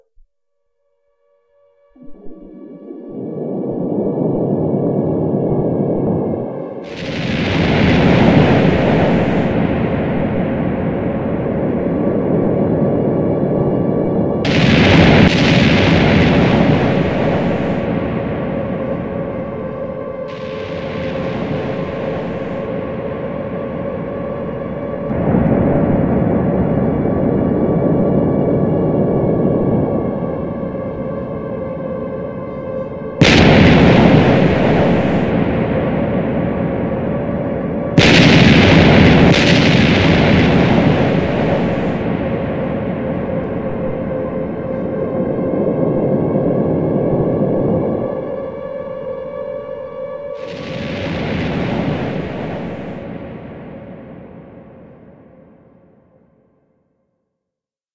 合成警报 " Carterattack
描述：二战时期的英国鸣放警报器。这是攻击信号（即将发生的空袭）。
Tag: 空袭 警报 攻击 紧急情况下 警笛 战争 警告 二战